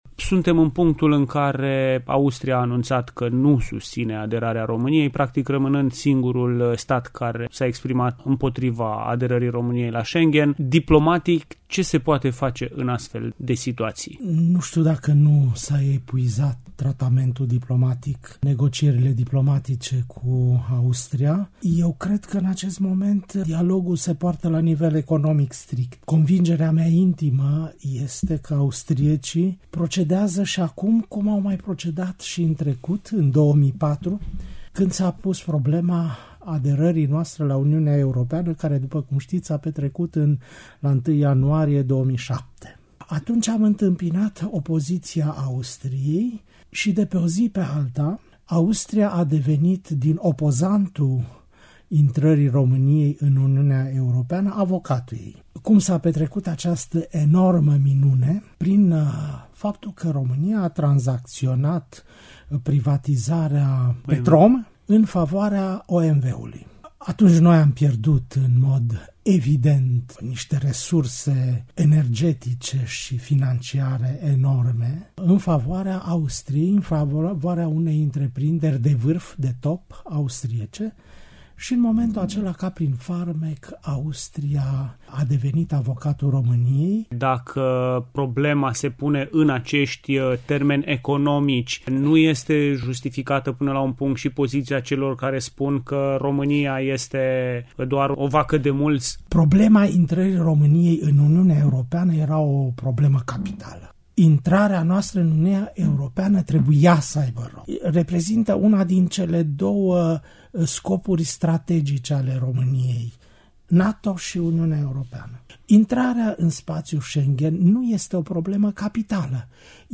Fostul ambasador Vasile Popovici, despre poziția Austriei privind aderarea la spațiul Schengen: Dialogul se poartă strict la nivel economic/ INTERVIU
interviu-vasile-popovici-schengen.mp3